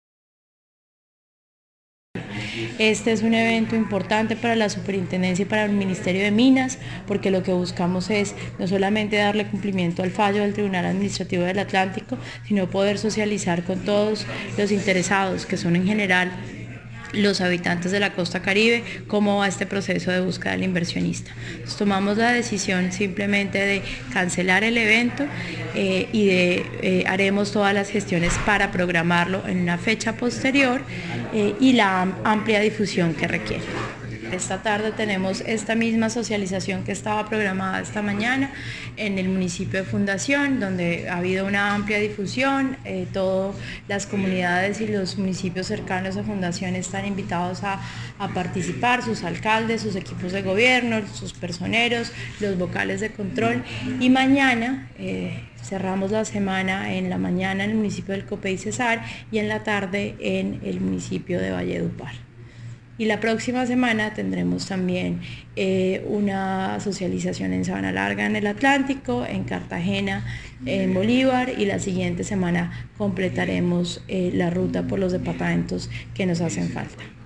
Adjuntamos audio declaraciones superintendente Natasha Avendaño García